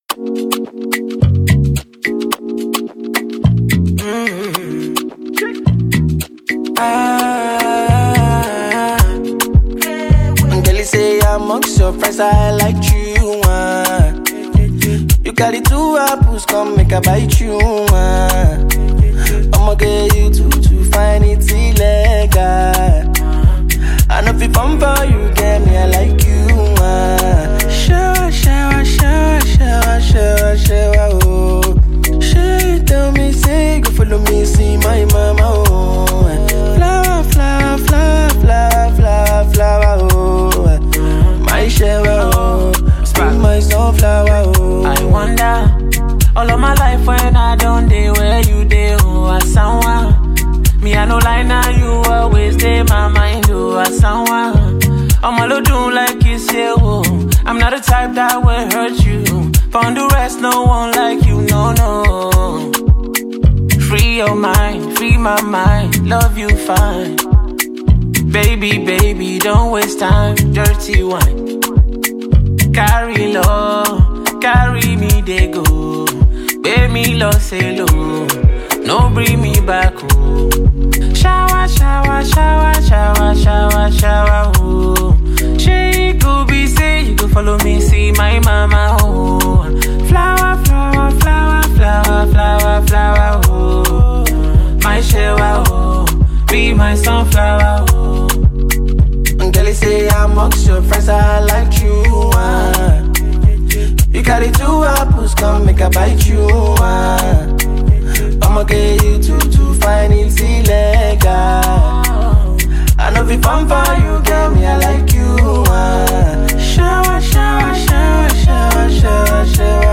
From soulful melodies to infectious beats